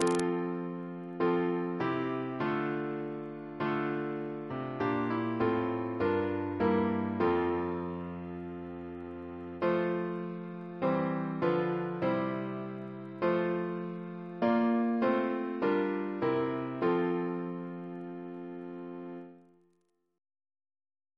Double chant in F Composer: Stephen Elvey (1805-1860), Organist of New College, Oxford; George's brother Reference psalters: ACB: 303; OCB: 62